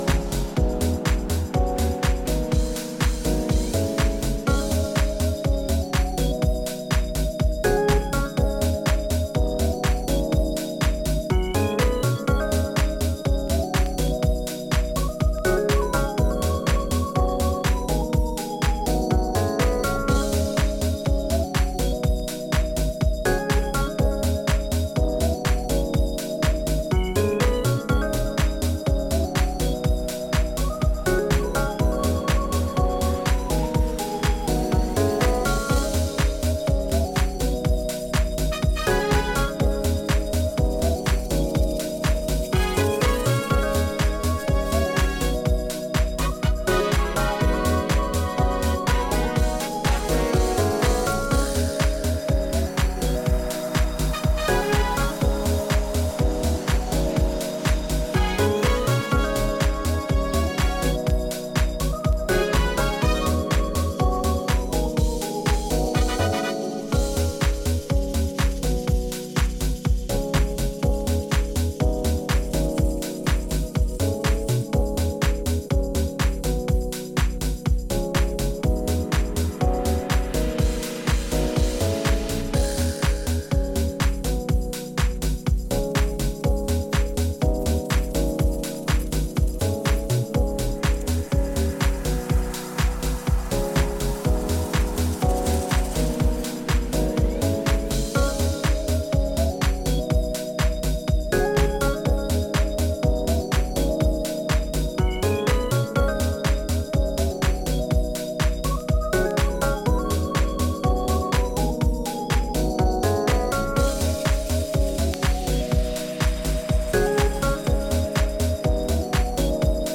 Italian deep house
lays down warm keys, rolling percussion and fluid basslines